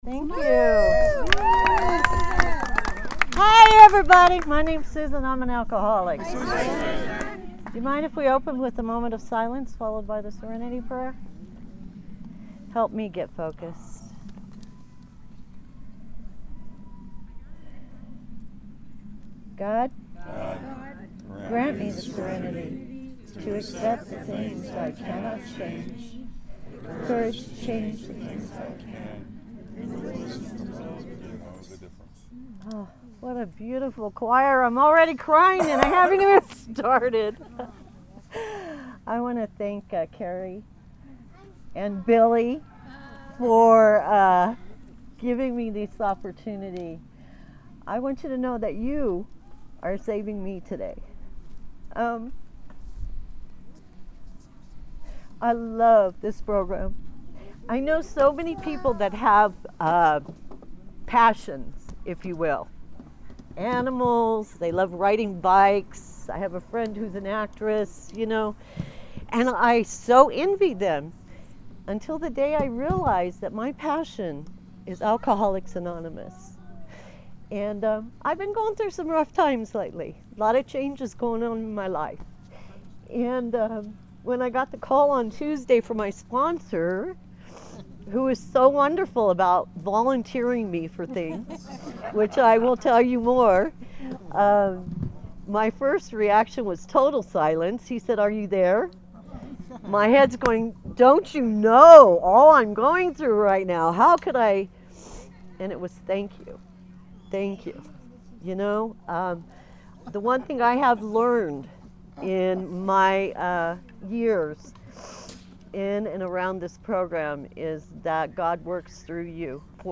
CNIA District 40 Unity Day Oct 2022